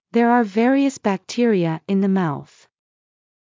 ｾﾞｱ ﾗｰ ｳﾞｧﾘｳｽ ﾊﾞｸﾃﾘｱ ｲﾝ ｻﾞ ﾏｳｽ